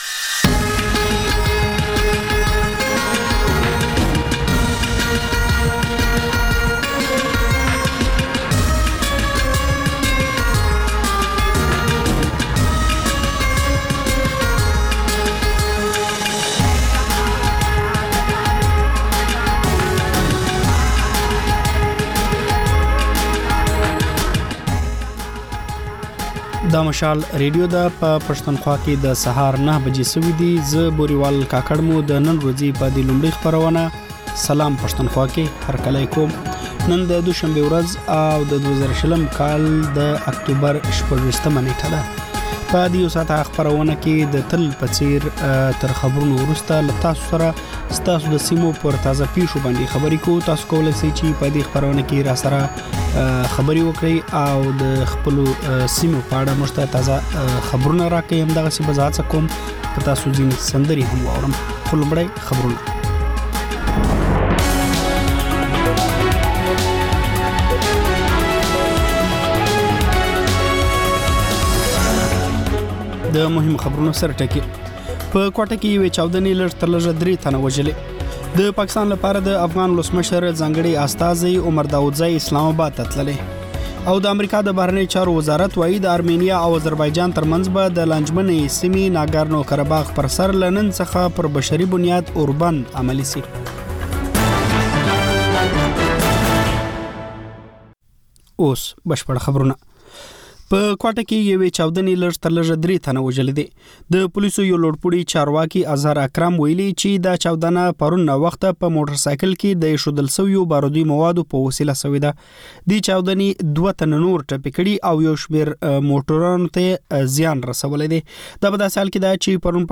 دا د مشال راډیو لومړۍ خپرونه ده چې په کې تر خبرونو وروسته رپورټونه، له خبریالانو خبرونه او سندرې در خپروو.